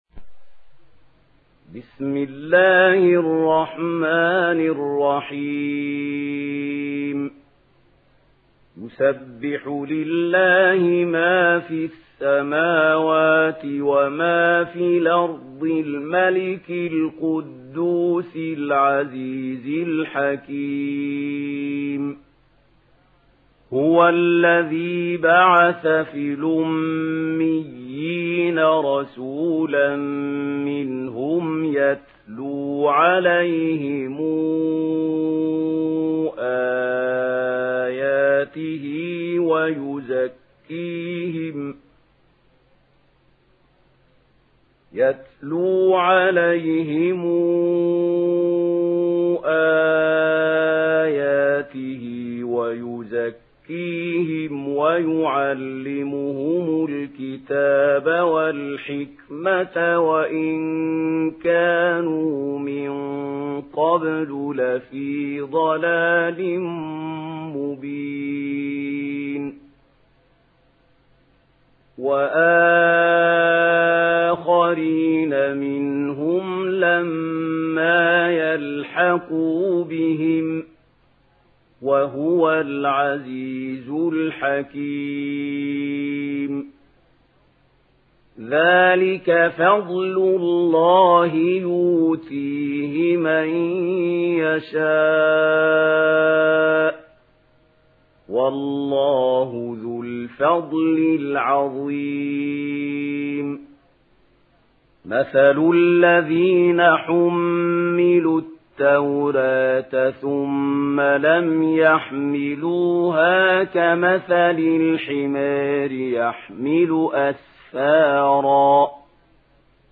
Surah আল-জুমু‘আ MP3 in the Voice of Mahmoud Khalil Al-Hussary in Warsh Narration
Murattal Warsh An Nafi